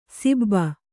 ♪ sibba